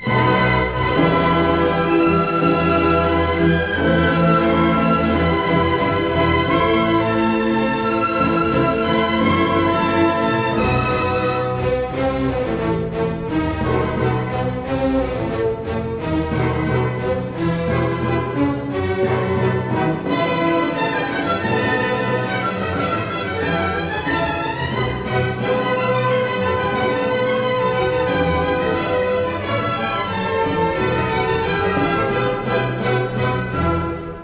Concerto for violin and orchestra in e minor